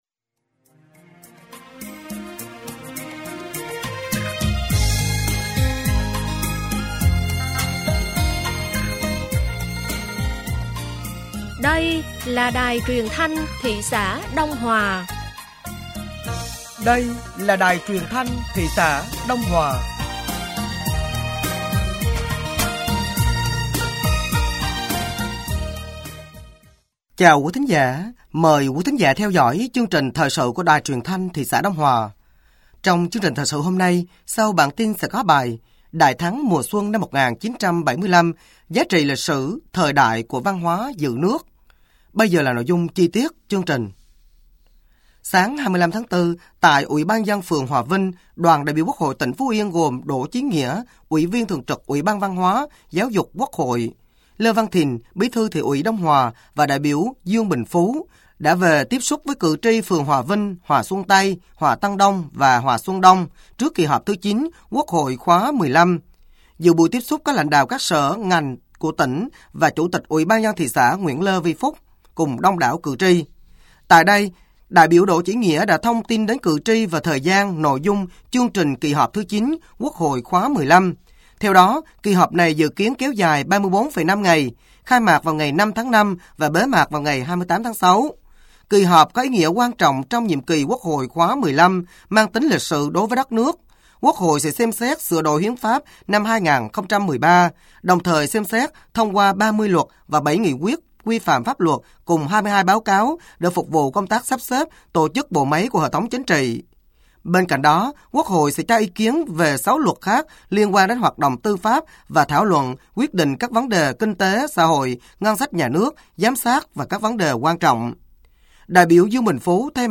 Thời sự tối ngày 25 và sáng ngày 26 tháng 4 năm 2025